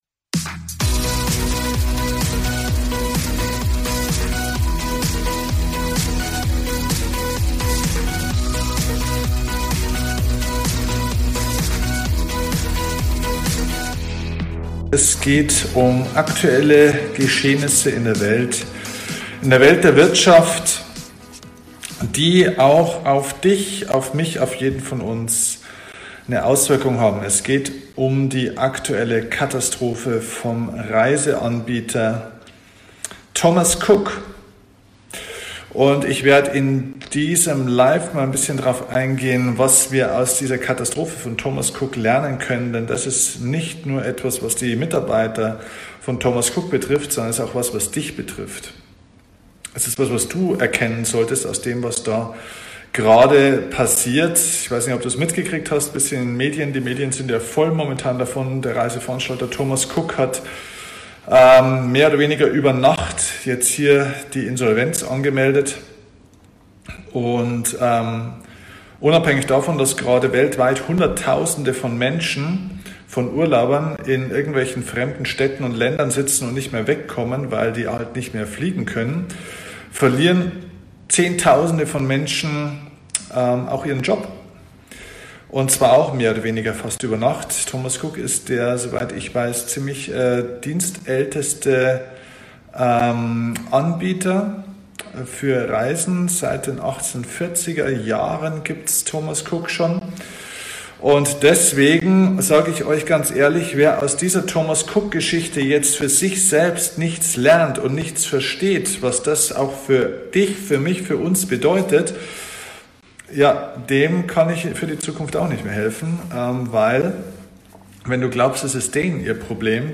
Nach den Ereignissen bei dem Reise-Riesen Thomas Cook habe ich einen Facebook Livestream gestartet. Bei diesem Livestream ging es darum, wie es überhaupt passieren konnte, dass ein so großes Unternehmen plötzlich Insolvenz anmelden musste und noch wichtiger: was das für uns alle bedeutet.